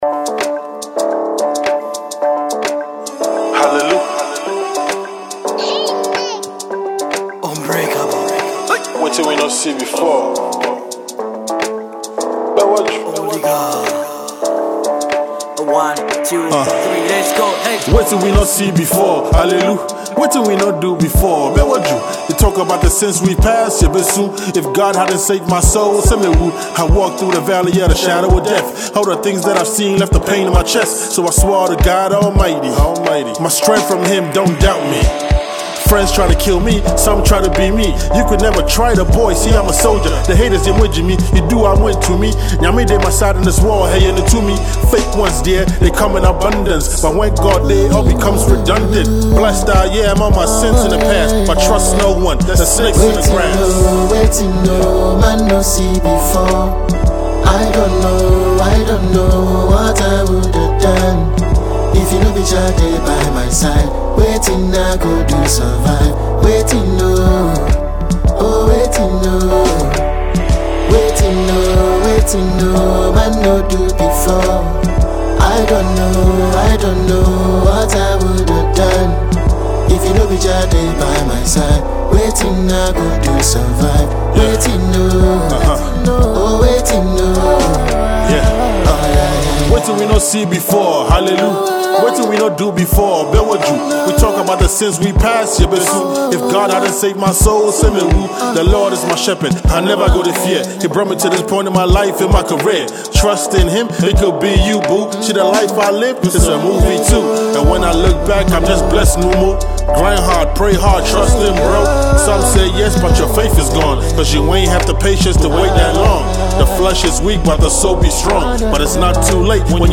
Enjoy this dope rap production.